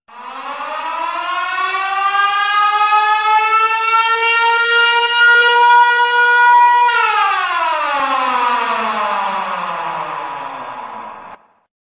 siren head.mp3